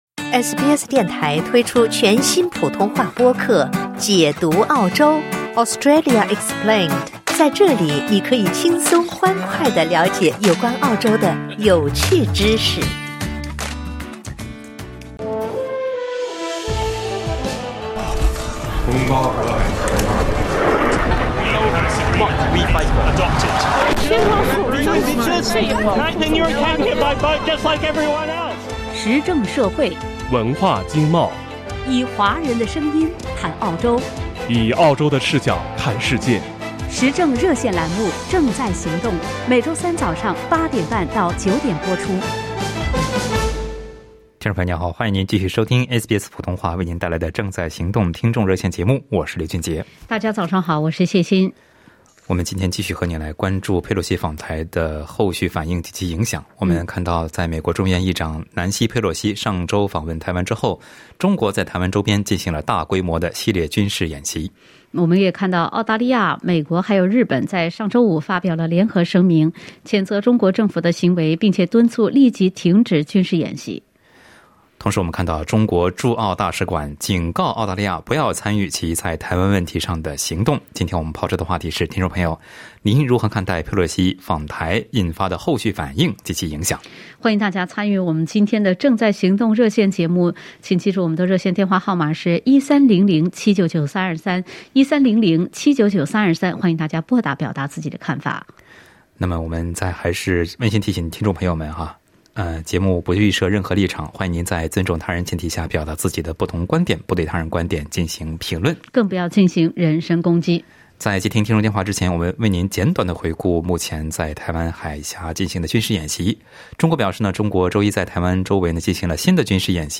热线听众仅代表其个人观点，不代表本台立场，仅供参考。